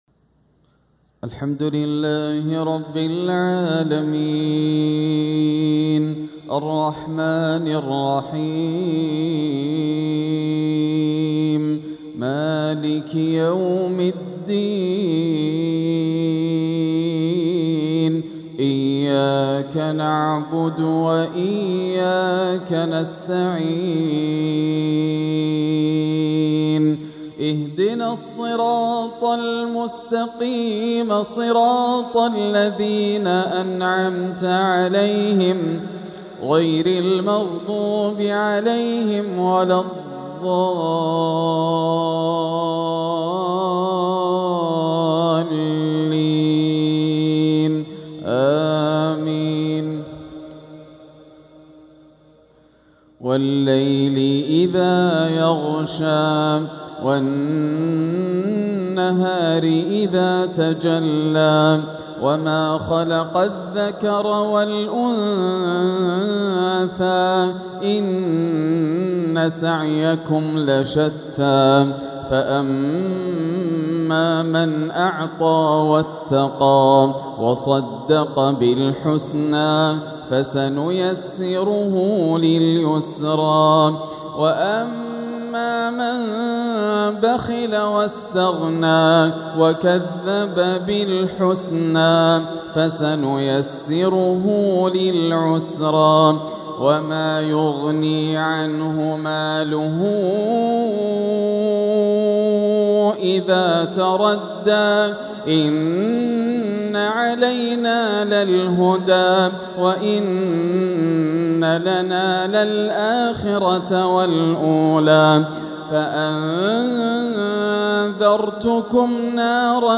تلاوة راائعة لسورتي الليل والتين | مغرب الثلاثاء 10 شوّال 1446هـ > عام 1446 > الفروض - تلاوات ياسر الدوسري